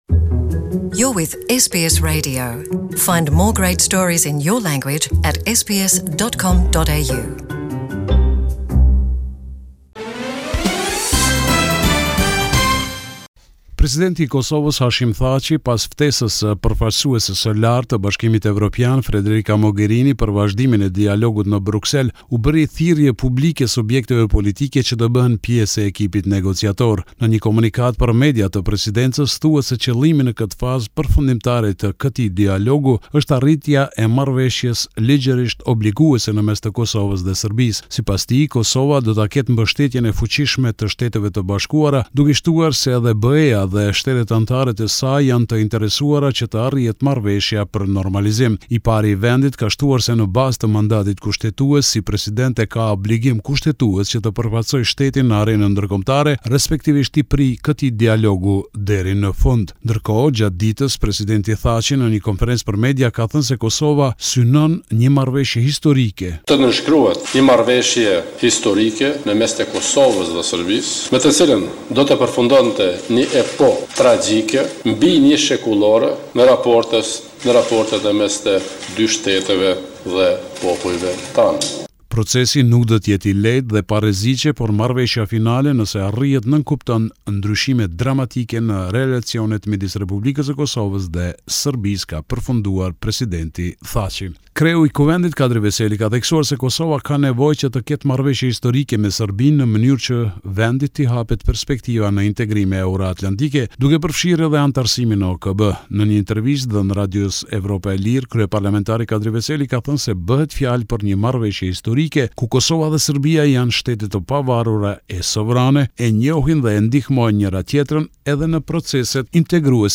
Sheshi Edit Durham, Prishtine (SBS Albanian ) This is a report summarising the latest developments in news and current affairs in Kosovo